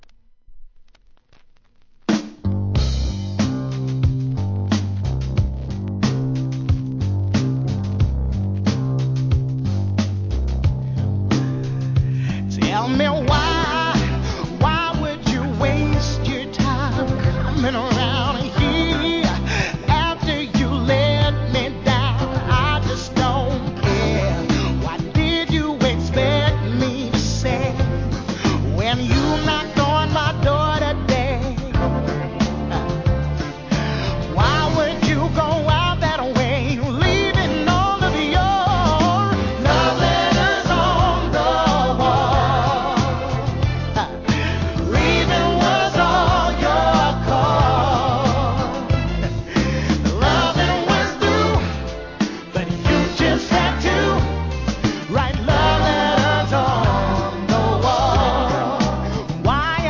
HIP HOP/R&B
UK SOUL